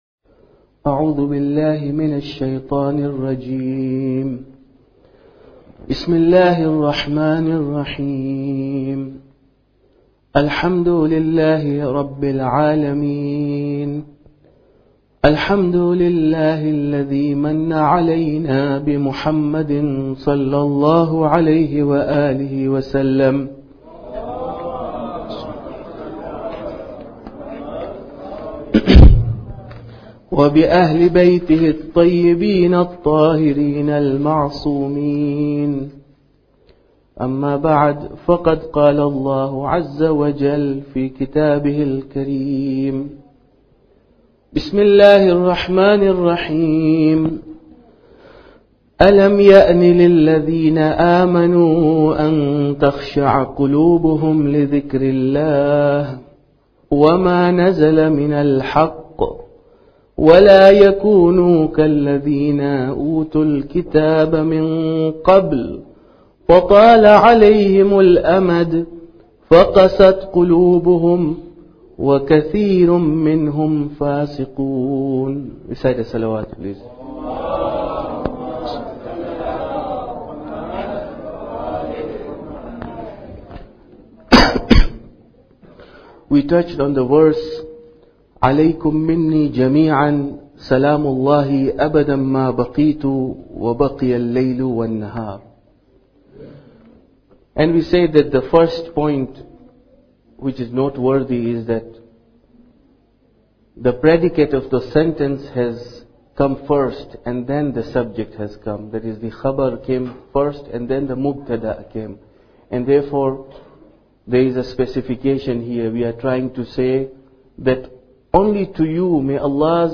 Muharram Lecture 8